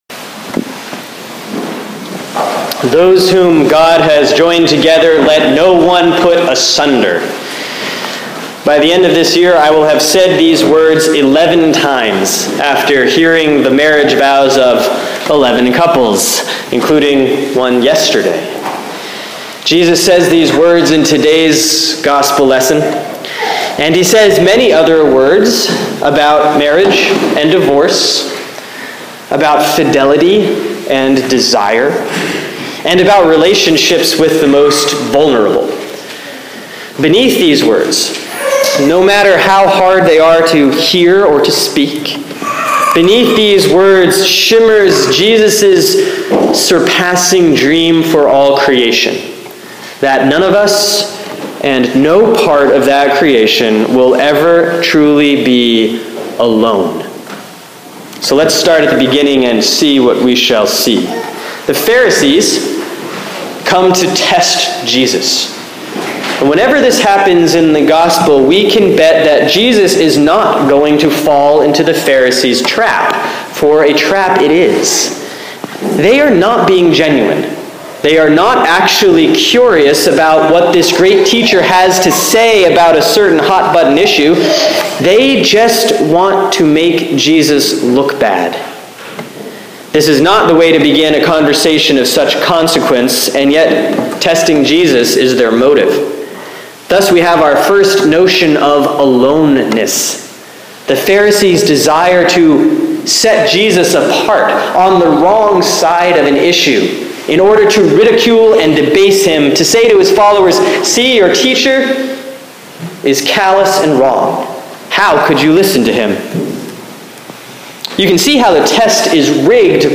Sermon for Sunday, October 4, 2015 || Proper 22B || Mark 10:2-16